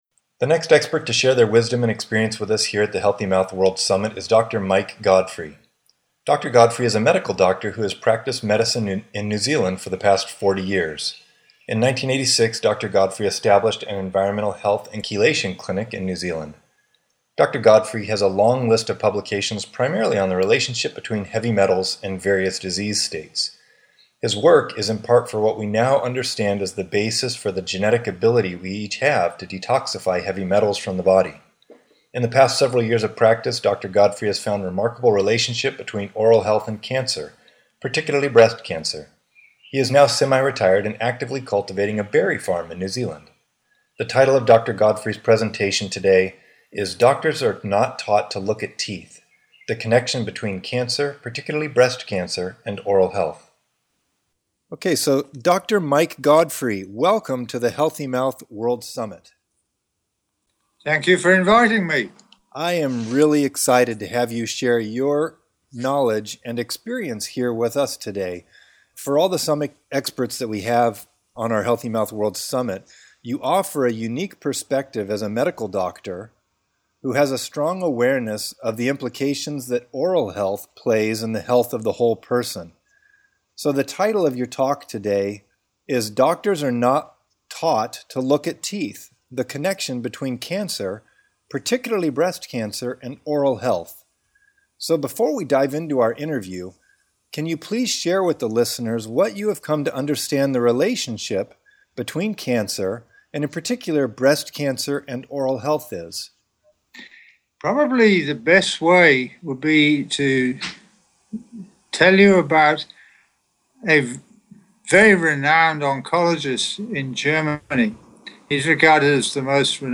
Expert Interview: Doctors Are Not Taught to Look at Teeth – The Cancer